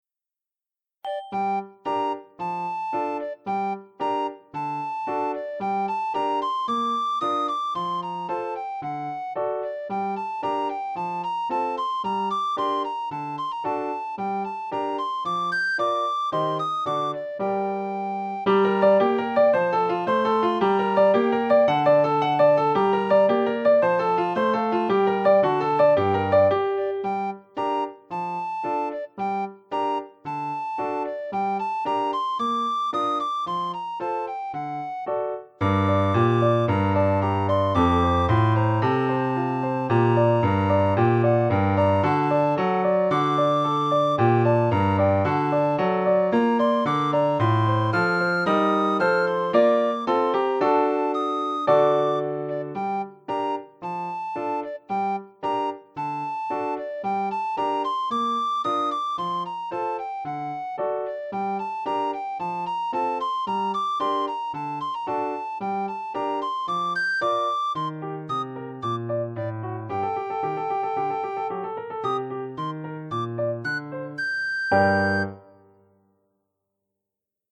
für Sopranblockflöte (Violine, Flöte) und Klavier